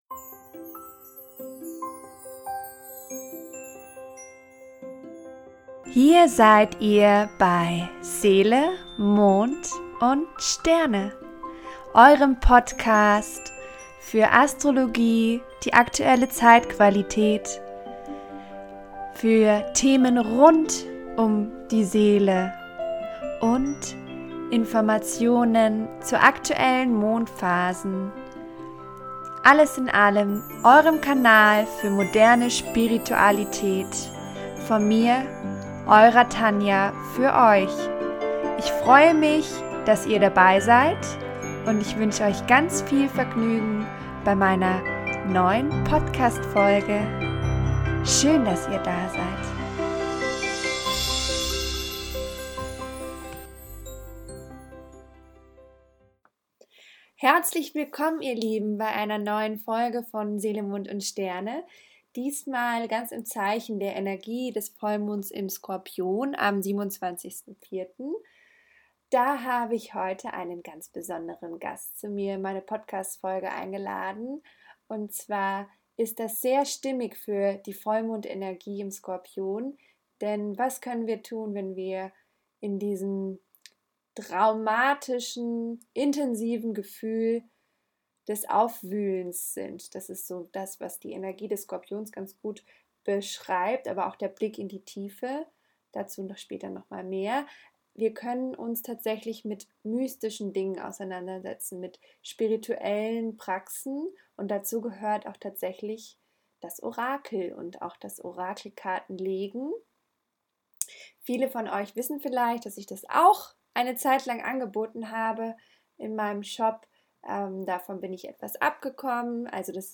Skorpion-Vollmond am 27.04 - Interview